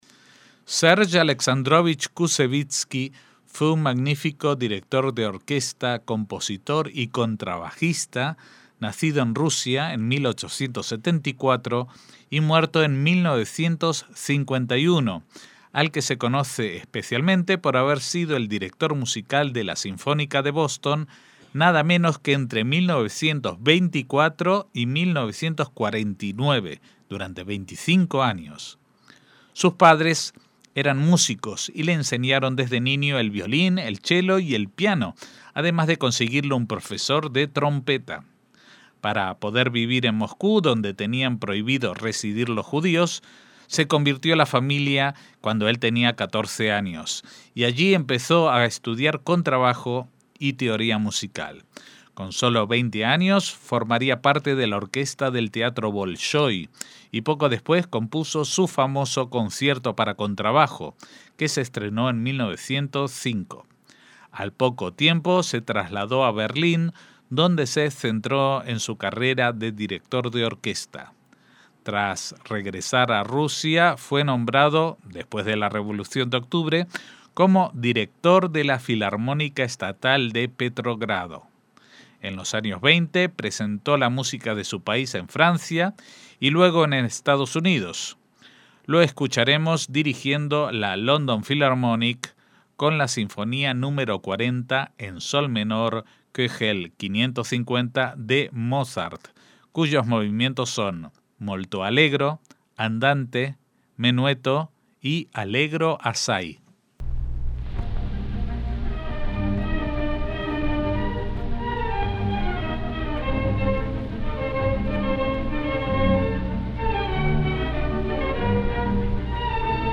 Serge Koussevitzky dirige la Sinfonía Nº 40 de Mozart
MÚSICA CLÁSICA